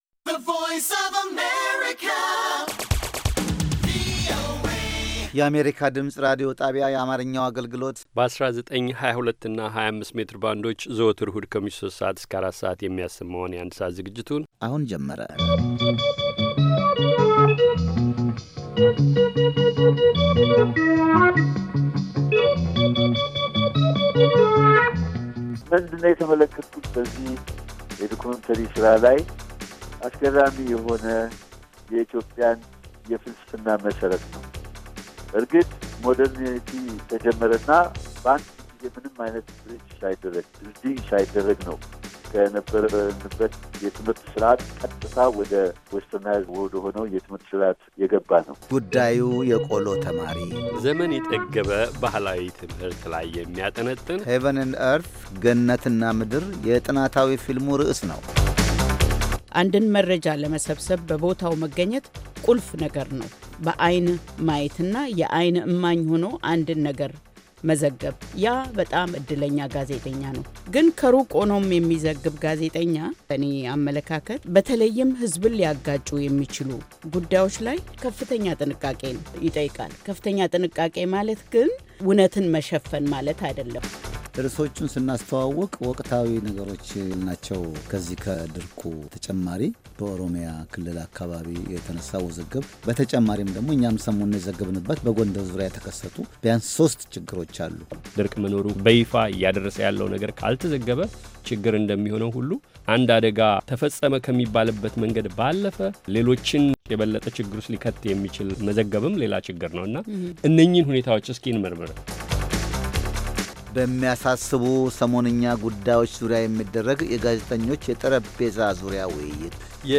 ከምሽቱ ሦስት ሰዓት የአማርኛ ዜና
ቪኦኤ በየዕለቱ ከምሽቱ 3 ሰዓት በኢትዮጵያ ኣቆጣጠር ጀምሮ በአማርኛ፣ በአጭር ሞገድ 22፣ 25 እና 31 ሜትር ባንድ የ60 ደቂቃ ሥርጭቱ ዜና፣ አበይት ዜናዎች ትንታኔና ሌሎችም ወቅታዊ መረጃዎችን የያዙ ፕሮግራሞች ያስተላልፋል። ዕሁድ፡- ራዲዮ መፅሔት፣ መስተዋት (የወጣቶች ፕሮግራም) - ሁለቱ ዝግጅቶች በየሣምንቱ ይፈራረቃሉ፡፡